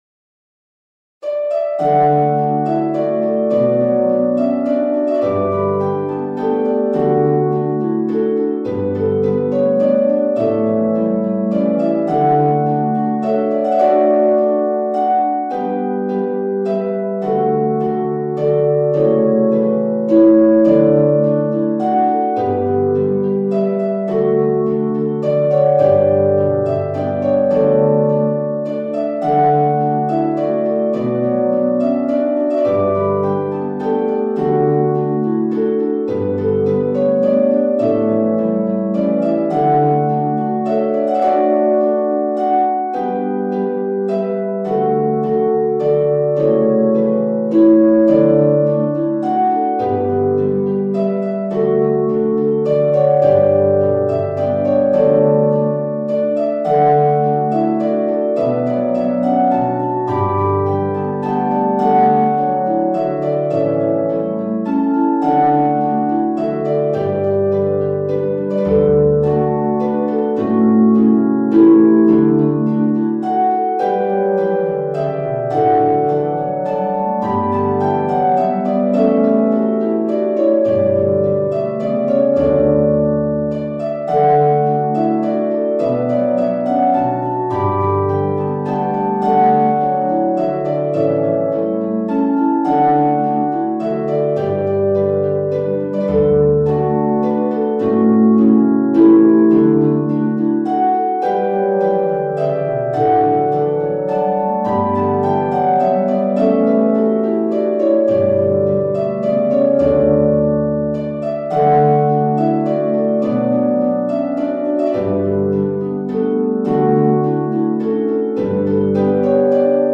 traditional
LEVER HARP
or PEDAL HARP...